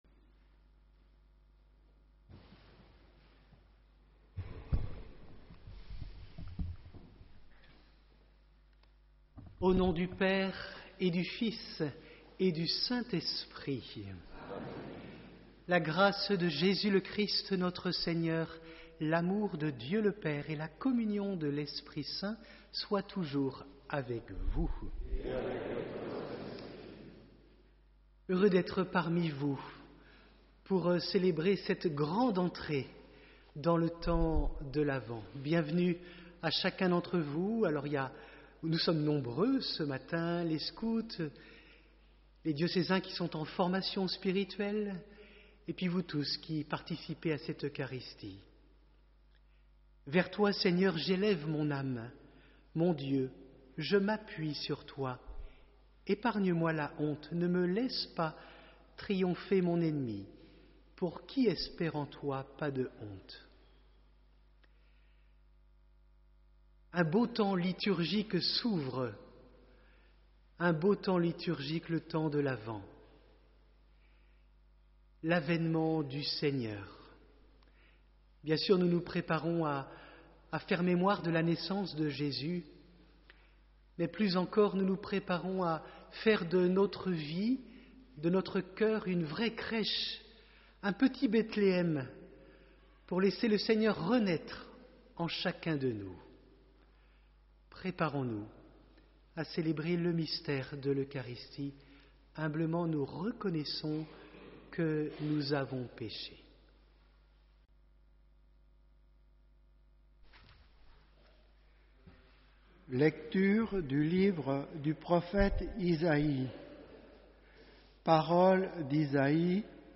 Vous pouvez retrouver l’intégralité de la MESSE sur Youtube